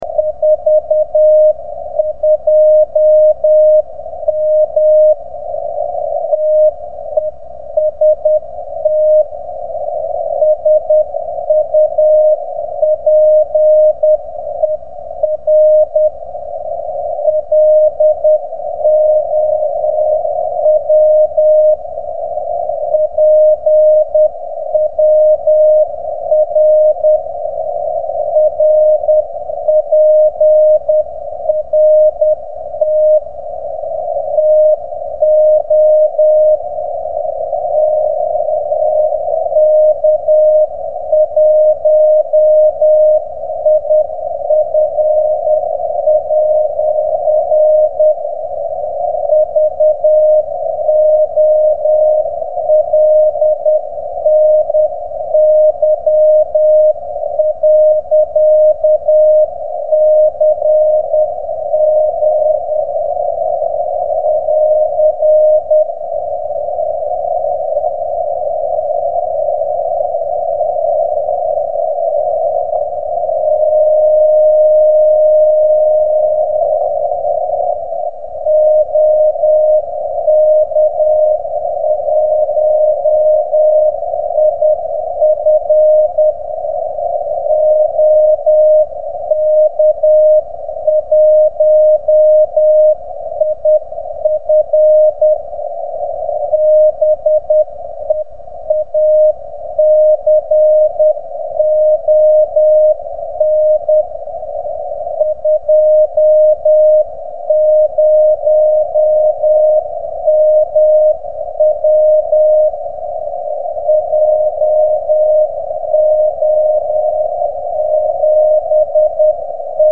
Když jsem pouštěl maják 20 mW, pak to nečinilo problémy vůbec nikomu.